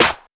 • Airy Acoustic Snare Sound A Key 73.wav
Royality free acoustic snare sample tuned to the A note.
airy-acoustic-snare-sound-a-key-73-hyj.wav